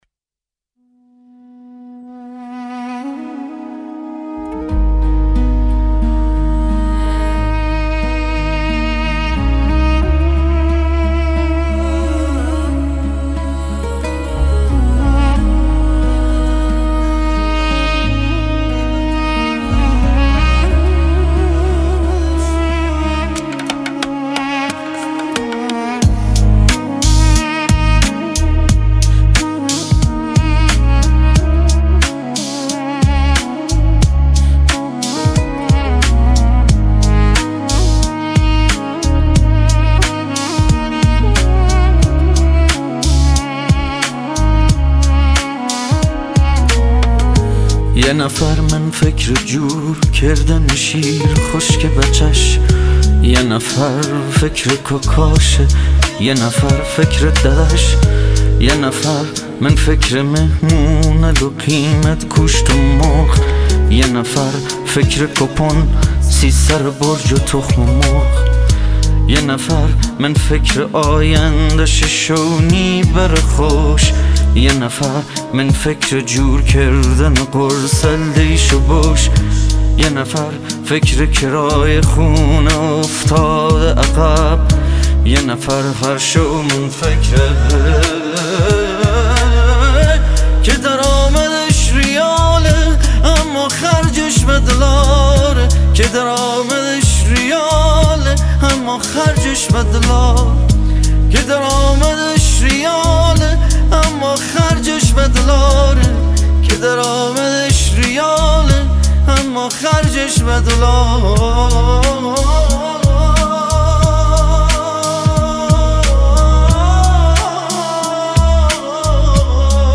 ترانه جدید